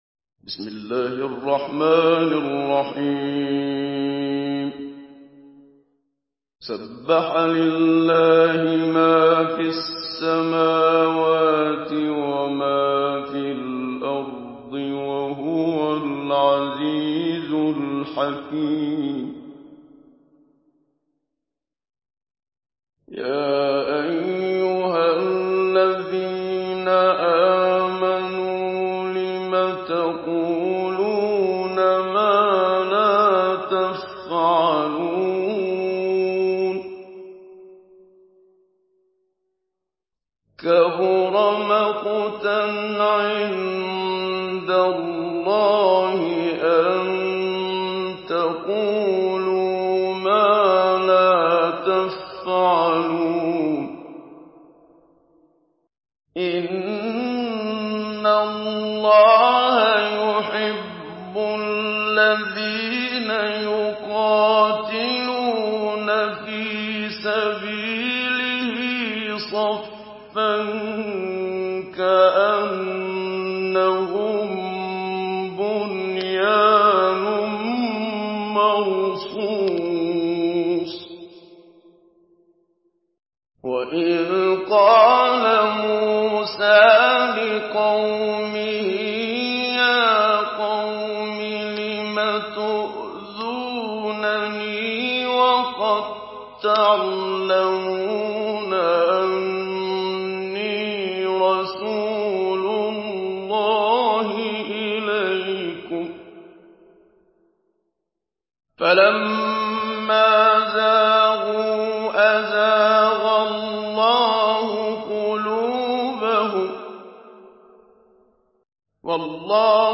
Sourate As-Saf MP3 à la voix de Muhammad Siddiq Minshawi Mujawwad par la narration Hafs
Une récitation touchante et belle des versets coraniques par la narration Hafs An Asim.